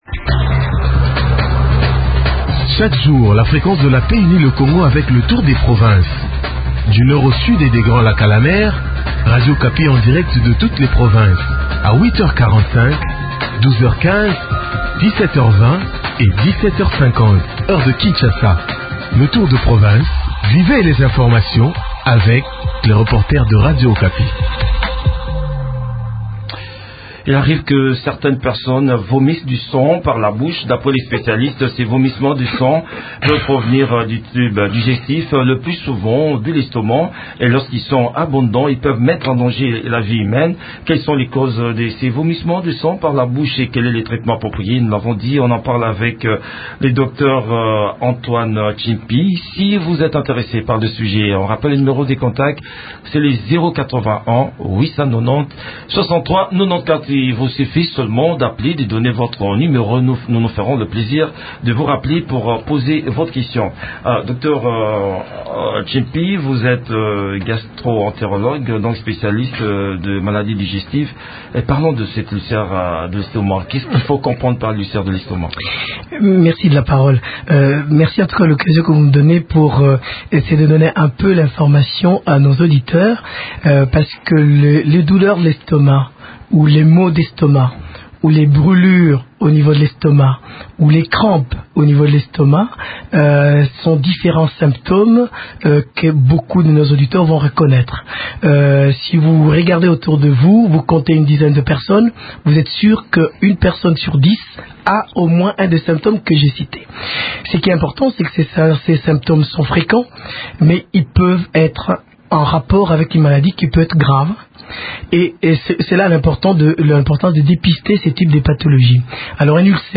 répond aux questions des auditeurs